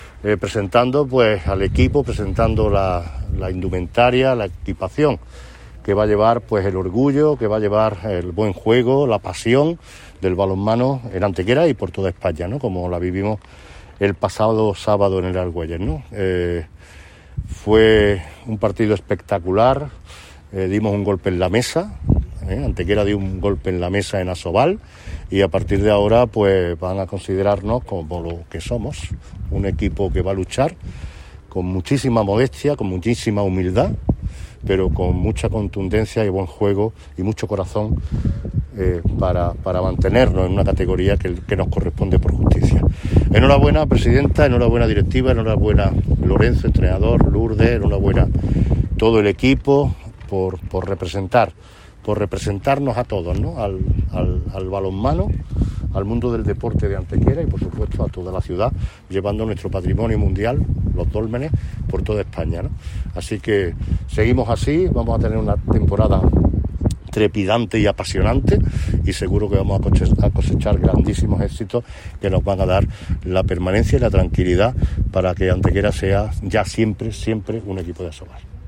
"Gracias a representarnos a toda la ciudad llevando también nuestro Patrimonio Mundial de los Dólmenes por toda España en lo que será una temporada trepidante y apasionante en la que seguro que cosecharemos grandes éxitos que nos aportarán la permanencia y la tranquilidad para que Antequera sea ya siempre un equipo de ASOBAL", declaraba ante los medios de comunicación Manolo Barón.
Cortes de voz